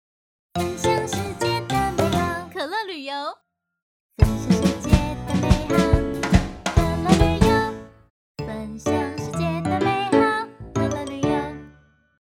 國語配音 女性配音員
廣告jingle提案配唱
她的聲音兼具年輕活力與感性溫度，能夠勝任動畫、有聲書以及各類多媒體作品的配音工作。